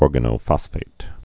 (ôrgə-nō-fŏsfāt, ôr-gănə-)